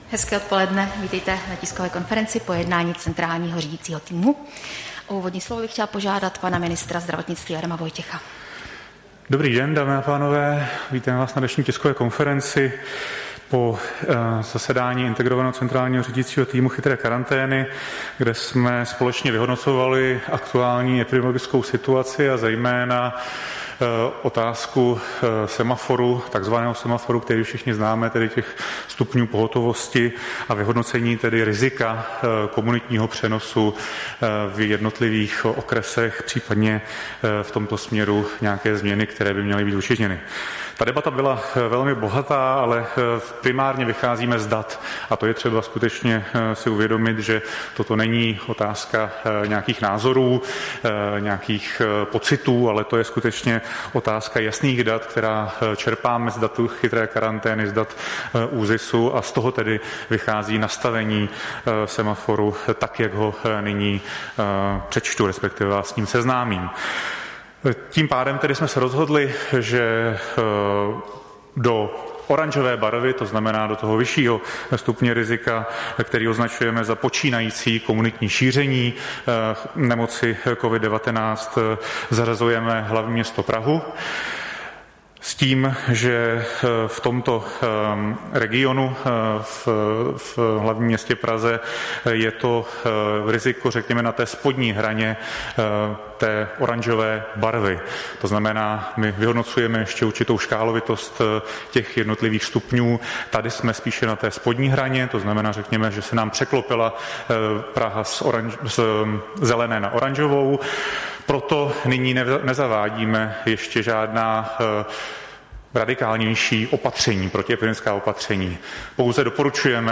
Tisková konference po jednání Integrovaného centrálního řídícího týmu Chytré karantény, 28. srpna 2020